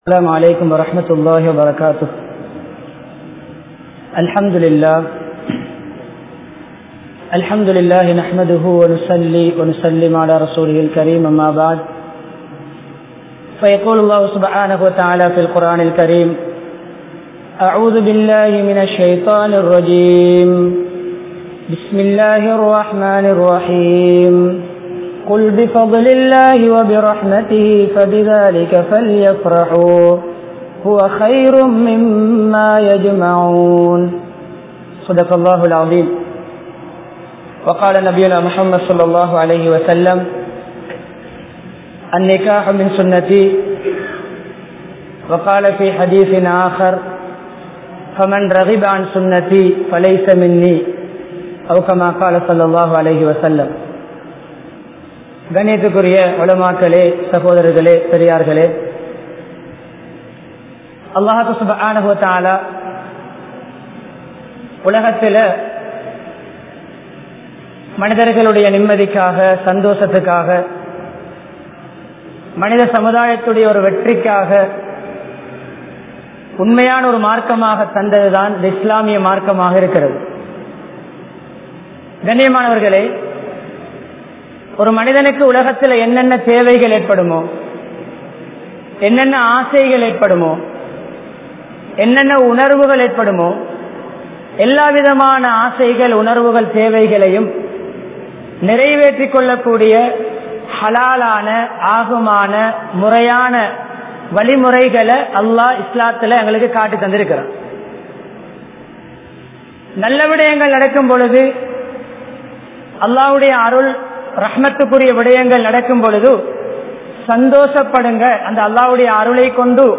Kanavan Manaivikkidaiel Purinthunarvu (கனவன் மனைவிக்கிடையில் புரிந்துணர்வு) | Audio Bayans | All Ceylon Muslim Youth Community | Addalaichenai